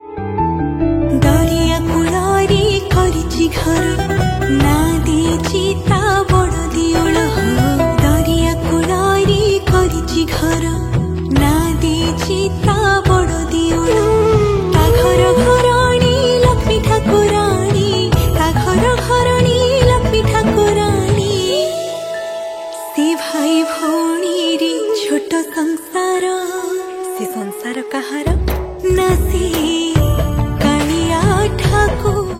Odia Bhajan Ringtones